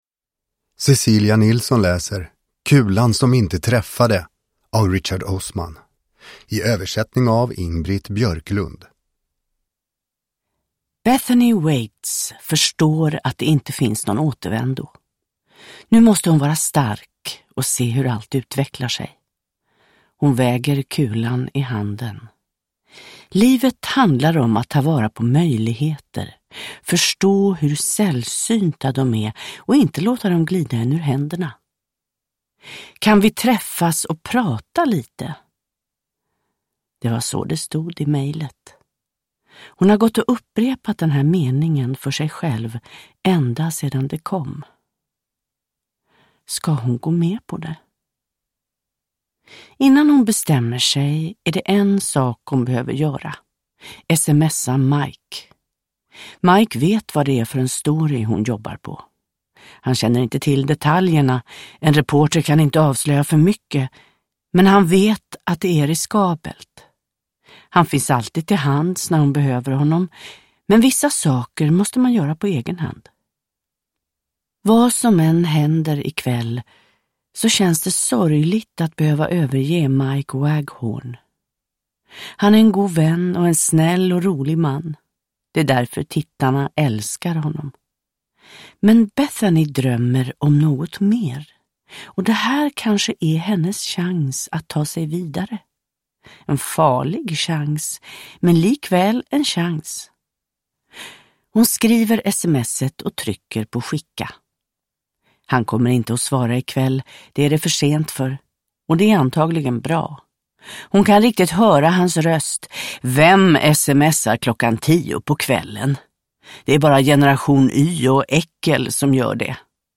Kulan som inte träffade – Ljudbok – Laddas ner
Uppläsare: Cecilia Nilsson